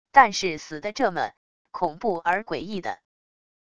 但是死的这么…恐怖而诡异的wav音频